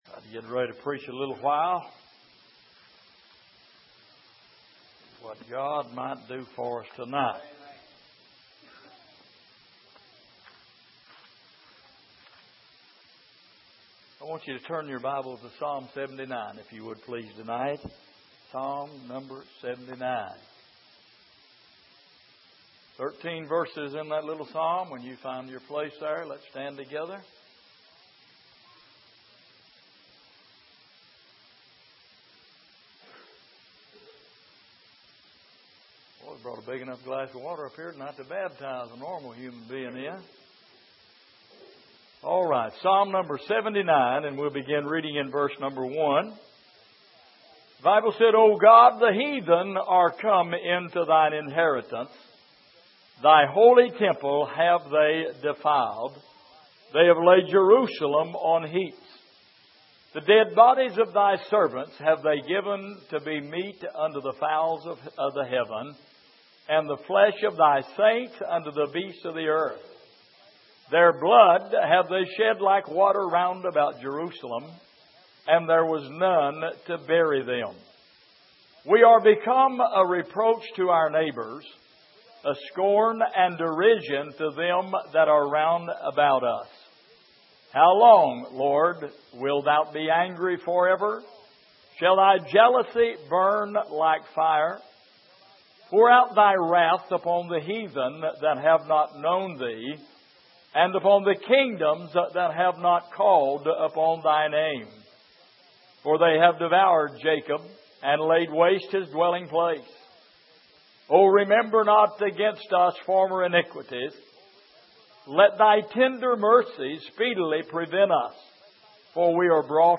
Exposition of the Psalms Passage: Psalm 79:1-13 Service: Midweek God’s Remedy For Sin « The House That Jesus Built Dear Jesus